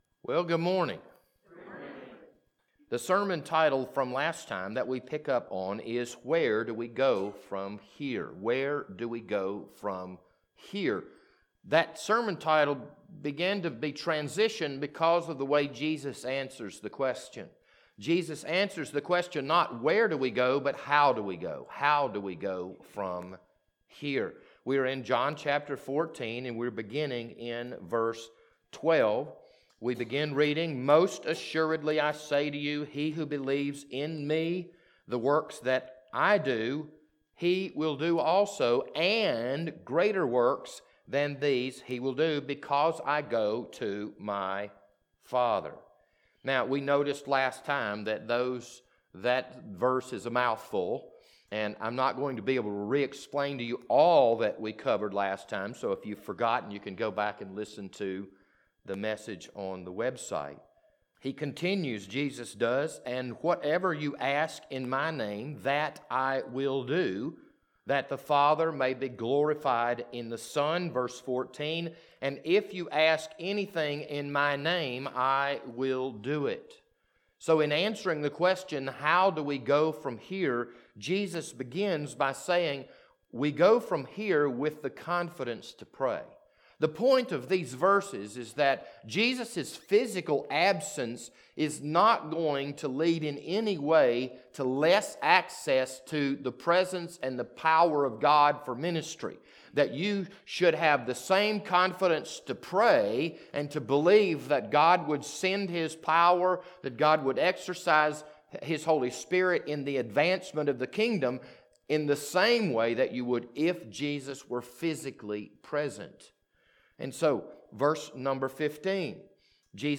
This Sunday morning sermon was recorded on January 3rd, 2021.